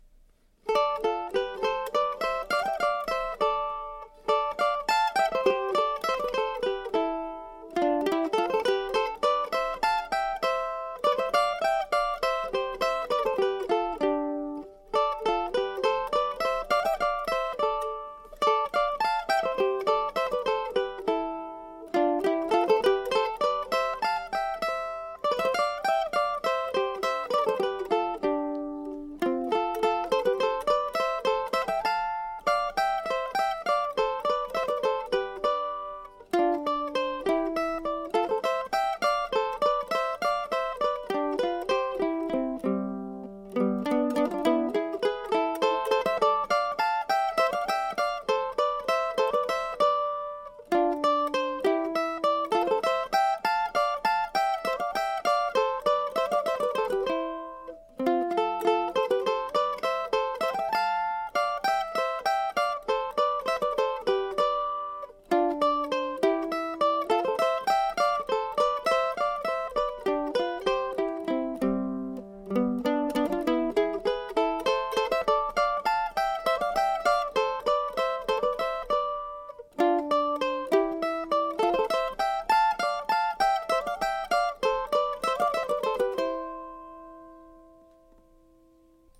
The recording was easy (although not perfect) but I took this opportunity to change the metronome markings on nos. 1,2,4,5,6 of the set.